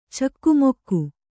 ♪ cakku mokku